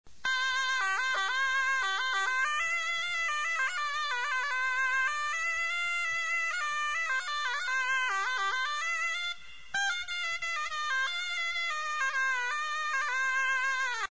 Zurna
Zurna is a musical instrument from the wooden wind family. It has a double reed for generating the sound.
It is considered an outdoor instrument since it is very loud.
zurna.mp3